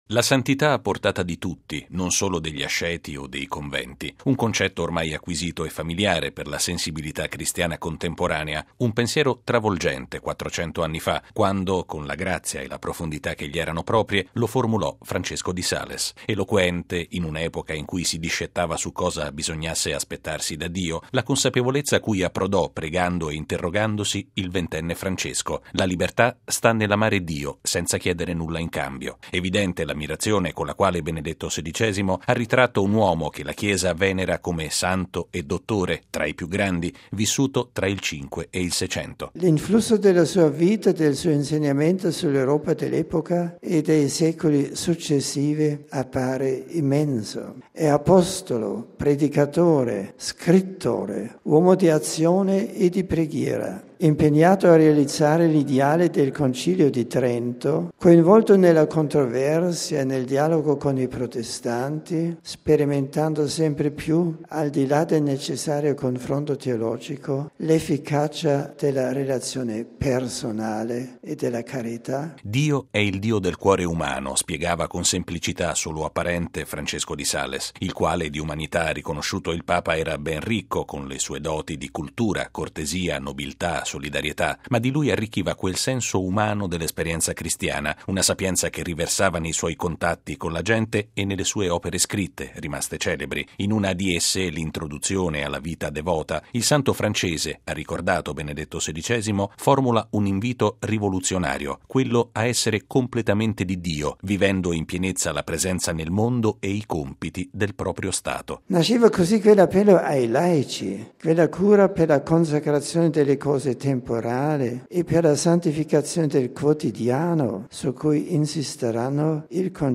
Lo ha affermato Benedetto XVI al termine della catechesi dell’udienza generale, tenuta questa mattina in Aula Paolo VI e dedicata a San Francesco di Sales. Il Papa ha parlato della grande amabilità e profondità d’animo del Santo, che insegnò che la santità è un percorso aperto a tutti, al di là del loro stato sociale.